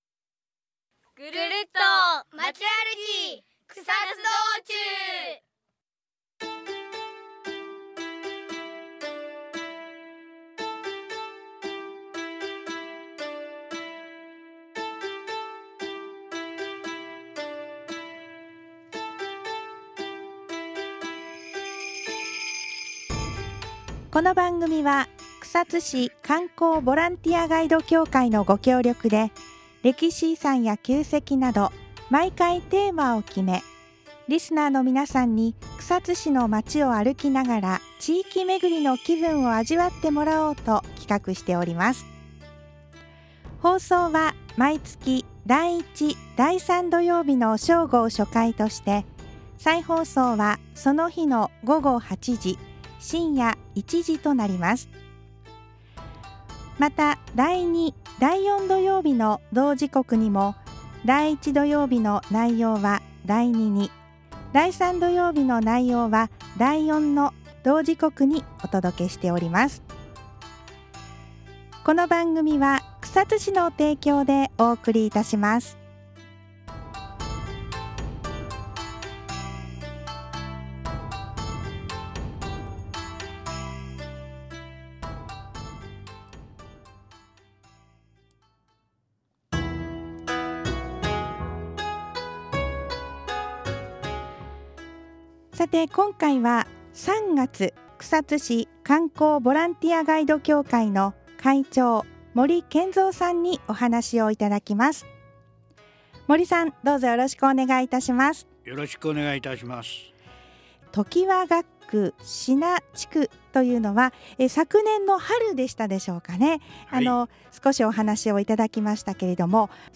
著作権の関係で、音楽は削除しています。 テーマは「常盤学区志那地区パート②」